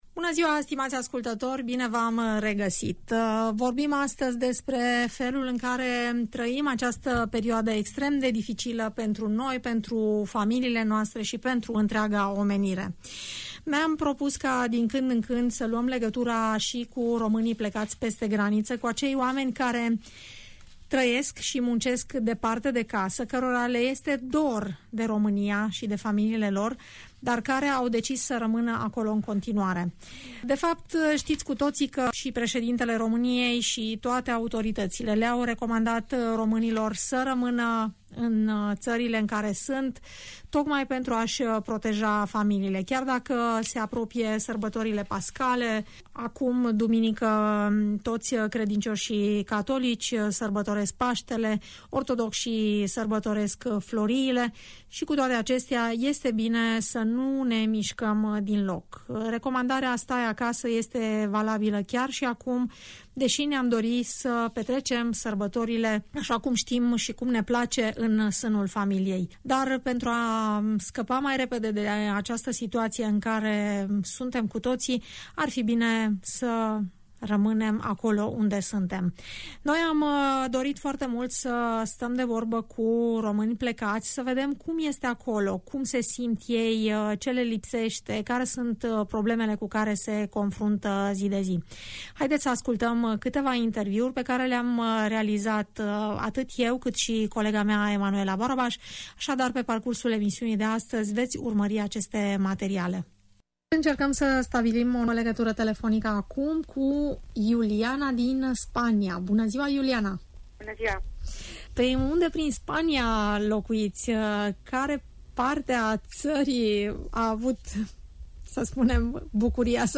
vei auzi confesiuni care îți vor merge direct la inimă!